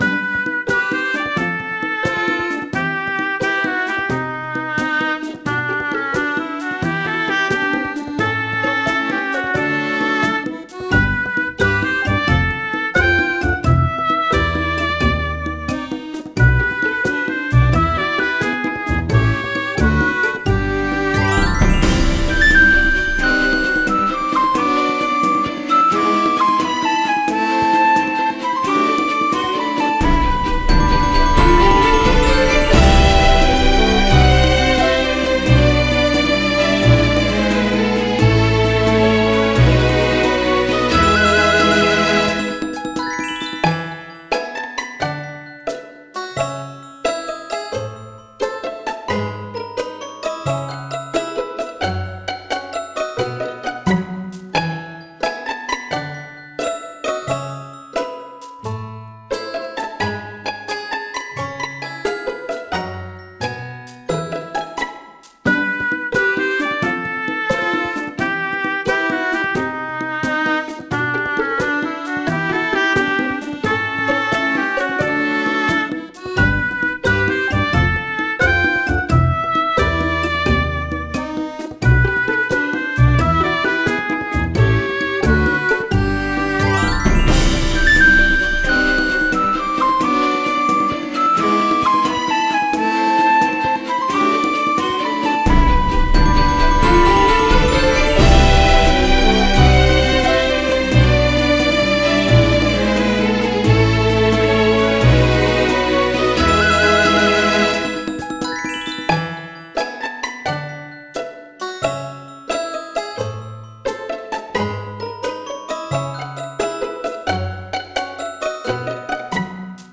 オーボエ+ストリングス+その他民族楽器と打楽器といった編成になってます
オーケストラ
ループ対応 ファンタジーな異世界系の街での日常BGMです。ループ対応。